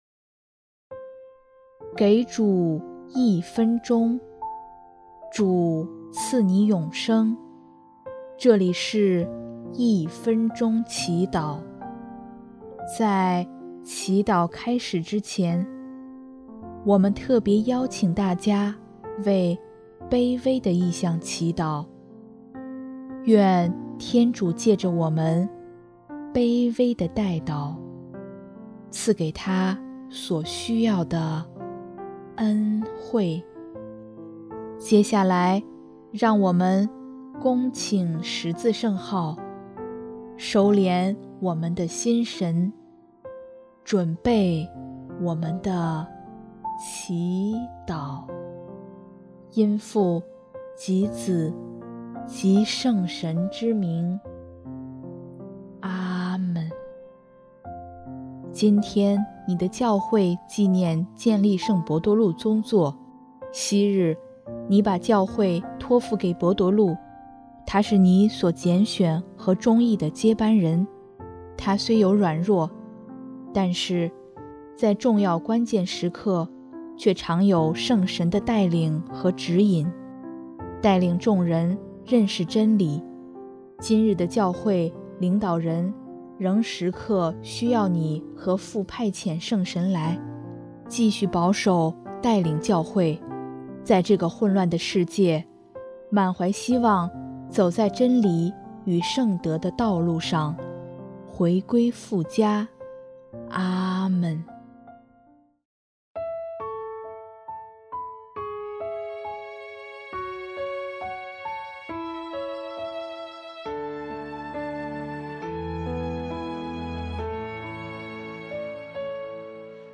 【一分钟祈祷】|2月22日 不是血肉之人启示了你，而是我在天之父